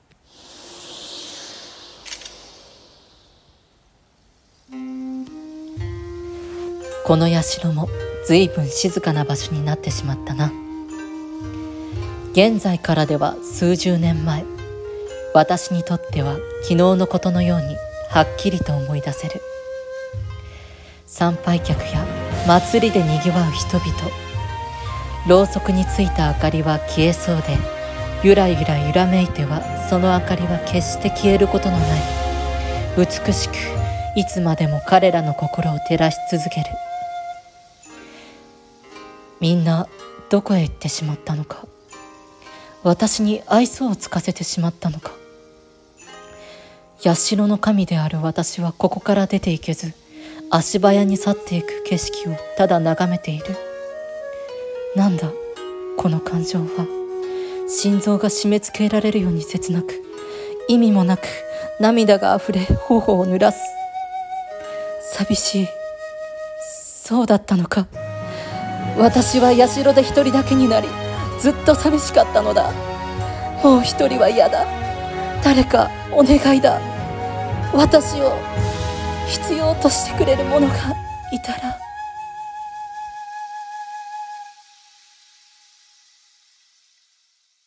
和風声劇台本】独りぼっちの神様。